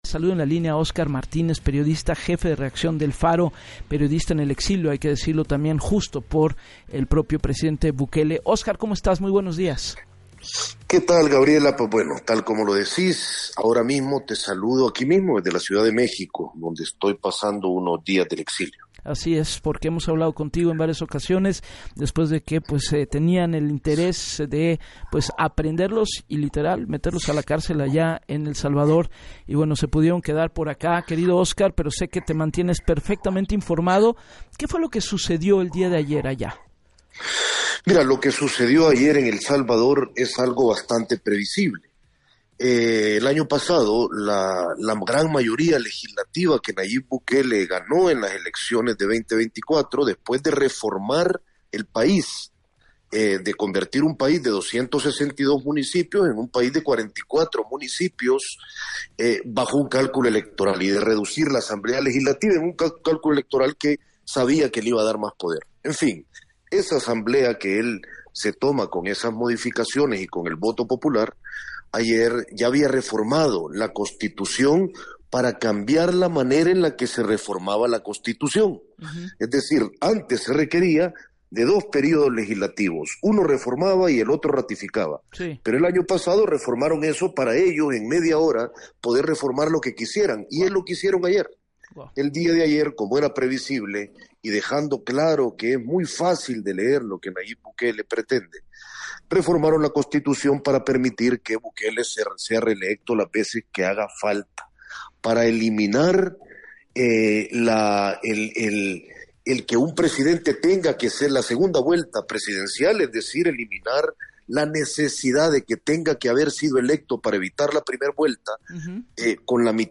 Desde el exilio en México, Óscar Martínez, jefe de redacción del medio El Faro, denunció en entrevista para “Así las Cosas” con Gabriela Warkentin, que “la Asamblea hecha a modo por Bukele modificó la Constitución para reformarla más fácilmente, y así pueda reelegirse cuantas veces quiera”.